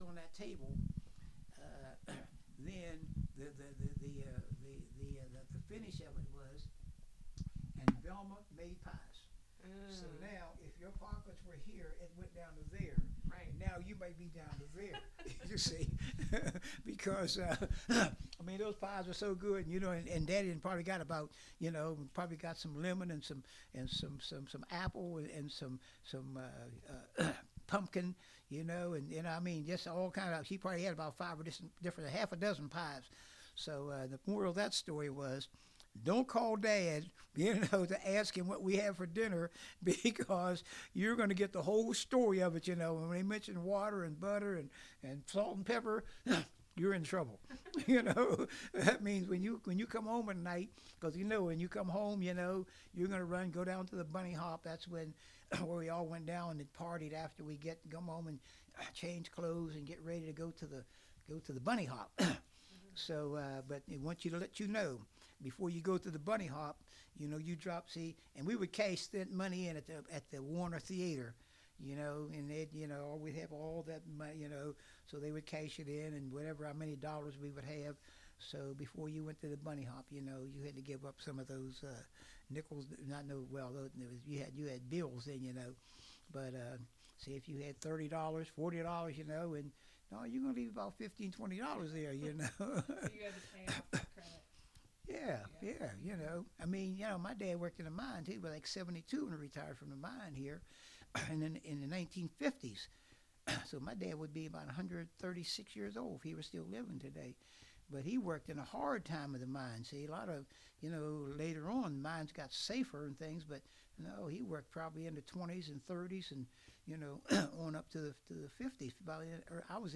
This interview is part of a collection of interviews conducted with Scotts Run natives/residents and/or members of the Scotts Run Museum.